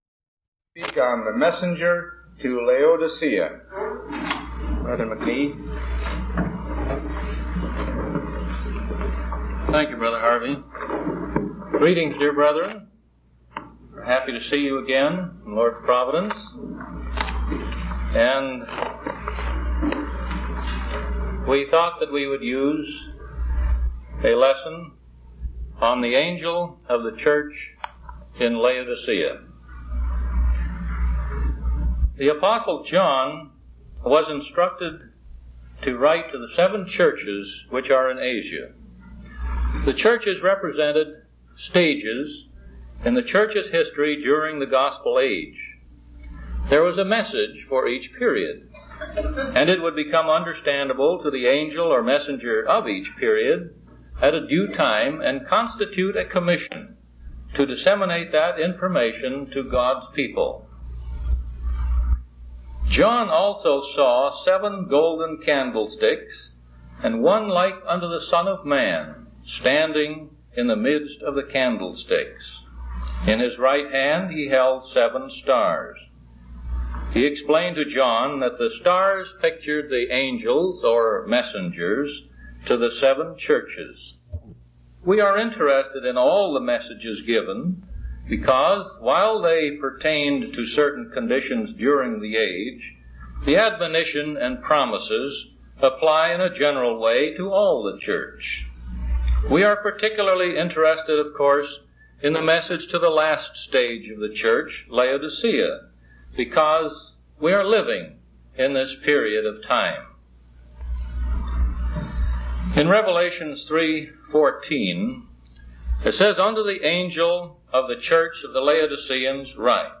From Type: "Discourse"